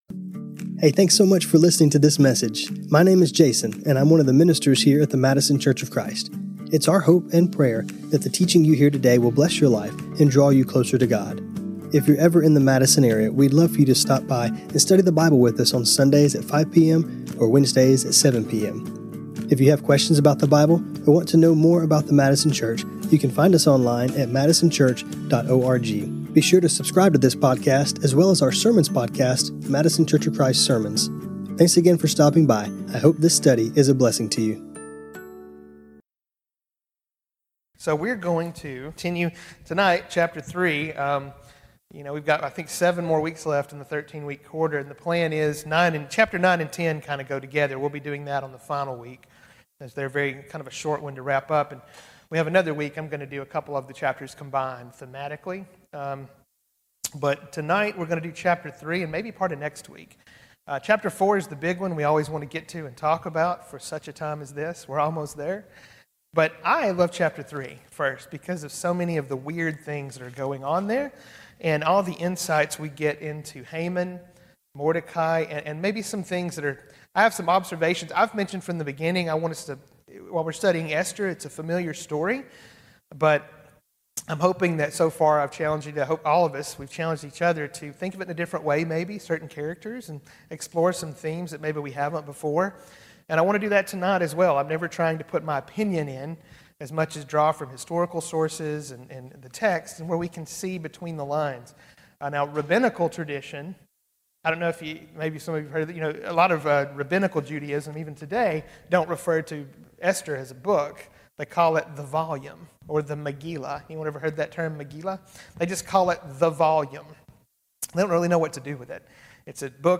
This class was recorded on Mar 11, 2026.